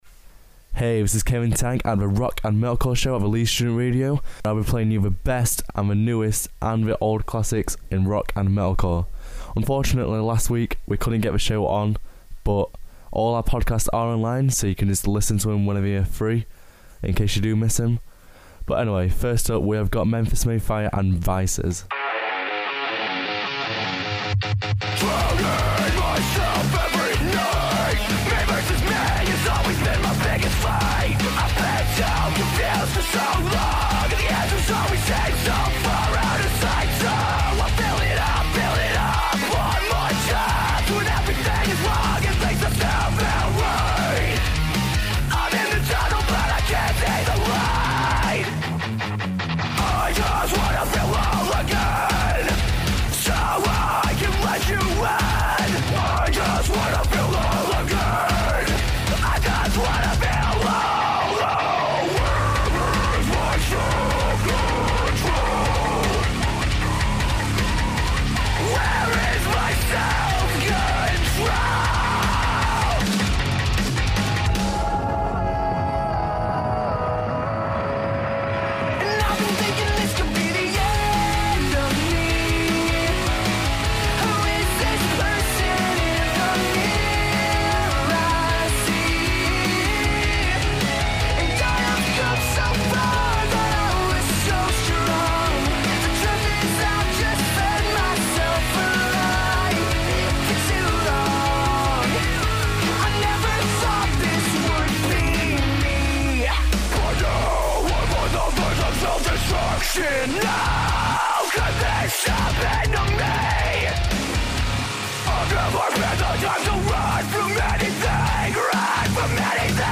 Here at the Rock and Metalcore Show we will be playing you the newest! and the best Rock and Metalcore there is to hear every Tuesday at Midnight!